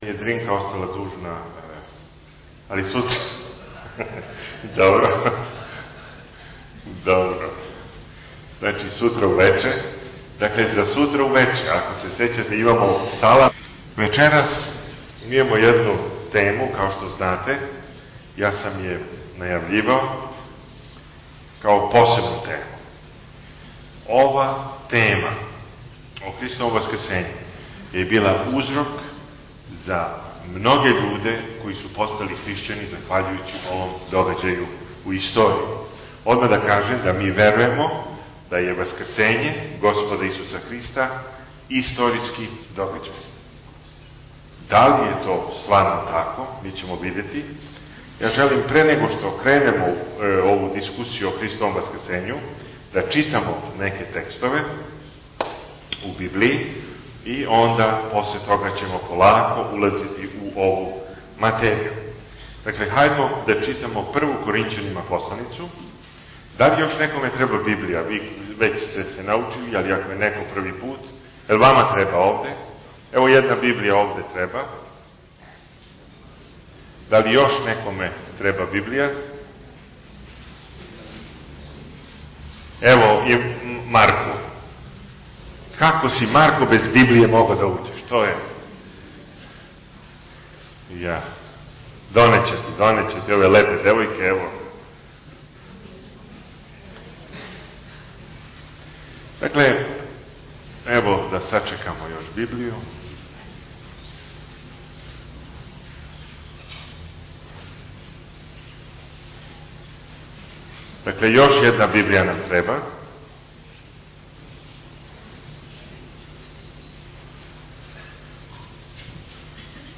on 2010-01-31 - Predavanja 1 - 8 mp3